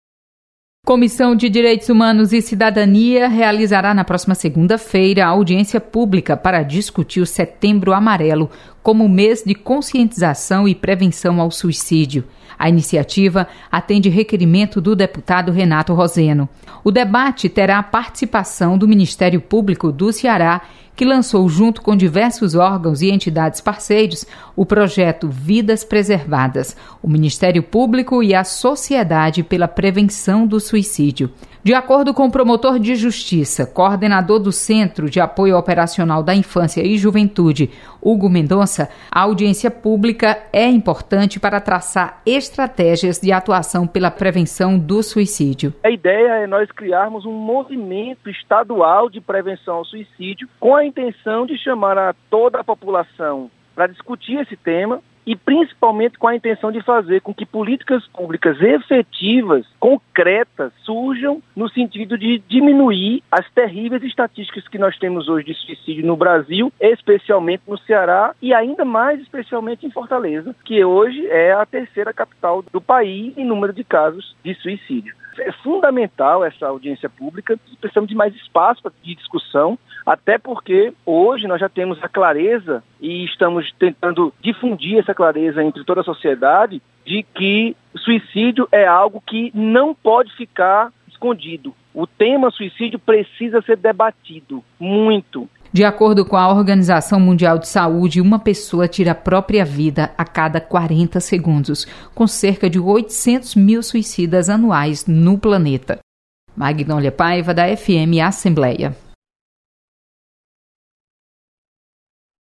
Audiência pública vai debater prevenção ao suicídio. Repórter